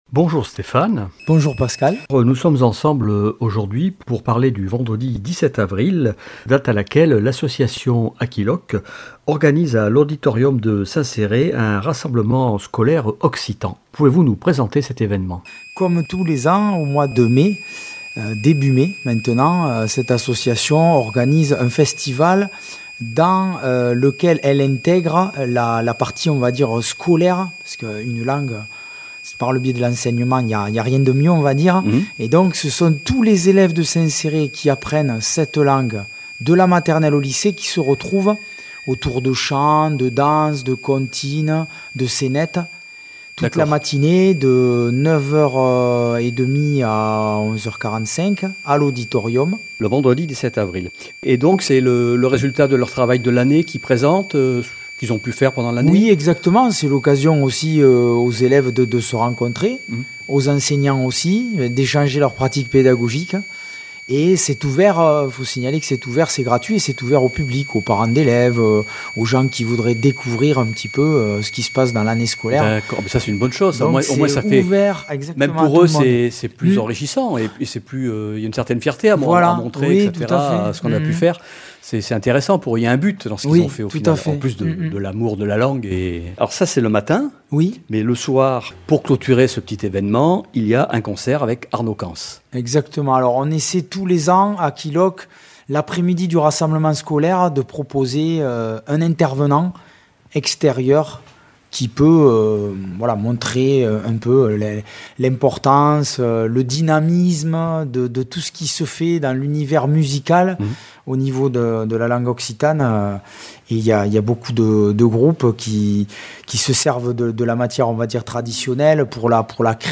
qui s'est rendu à l'espaci occitan carcinol à St Céré